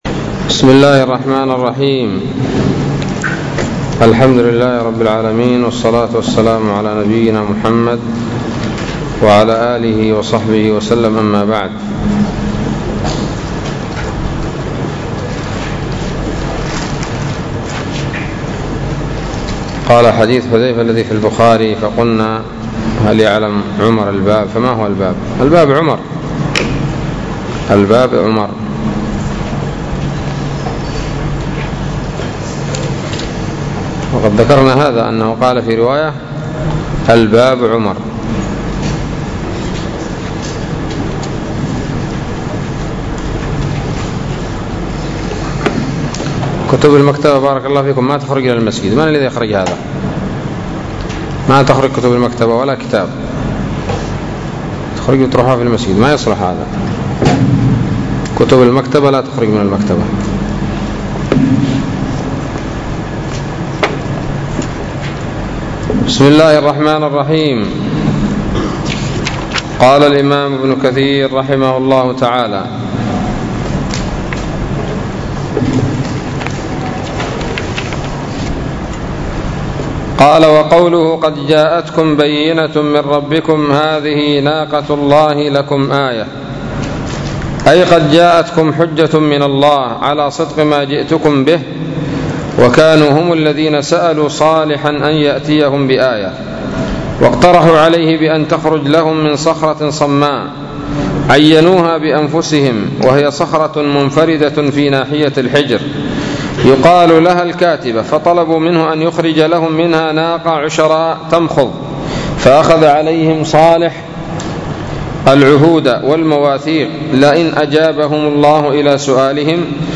الدرس الحادي والثلاثون من سورة الأعراف من تفسير ابن كثير رحمه الله تعالى